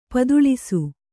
♪ paduḷisu